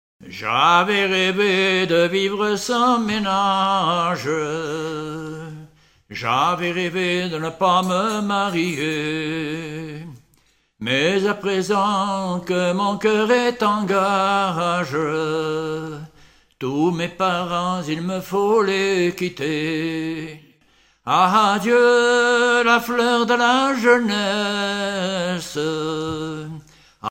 circonstance : fiançaille, noce ;
Genre strophique